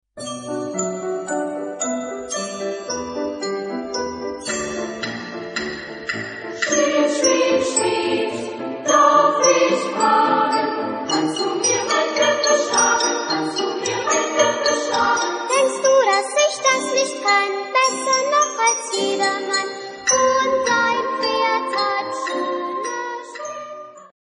Type de choeur : SSA  (3 voix égale(s) d'enfants )
Solistes : Sopran (1)  (1 soliste(s))